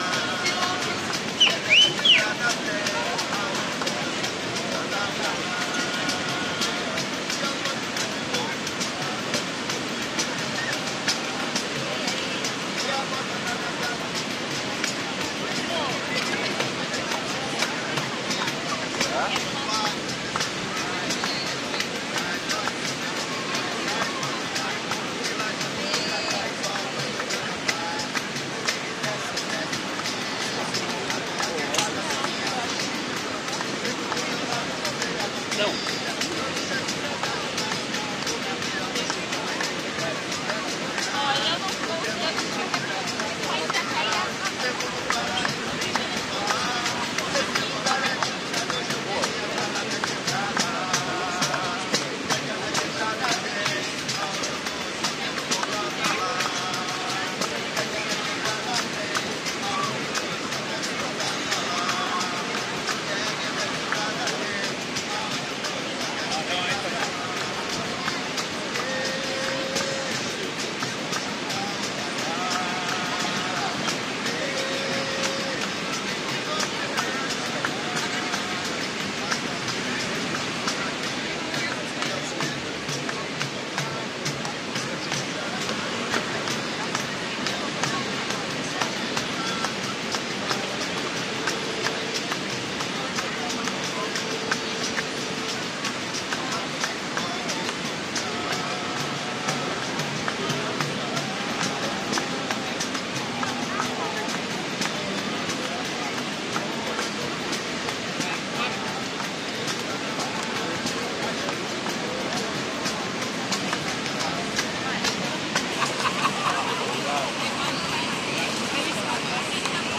oa-brasil-santa-catarina-praia-do-rosa-norte.mp3